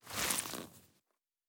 added stepping sounds
Wet_Snow_Mono_01.wav